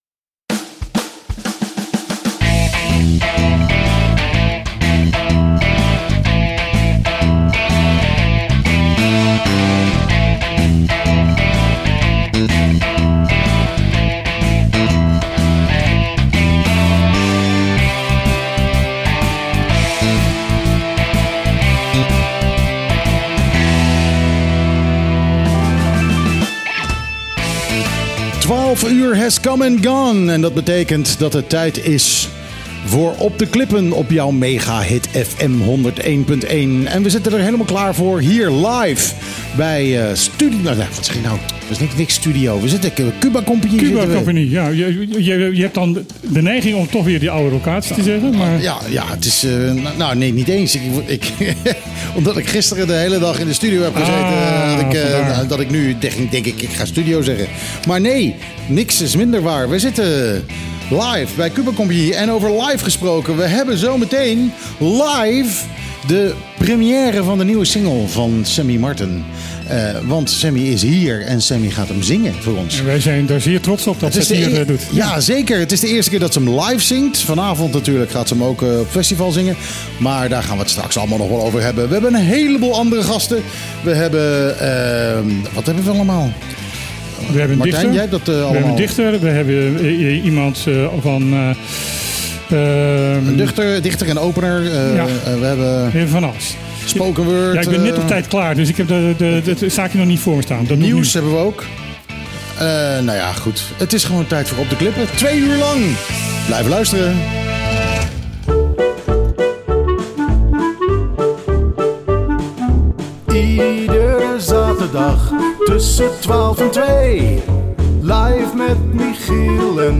Nu ook met live-muziek!
Natuurlijk werd ernaast gezongen ook gepraat.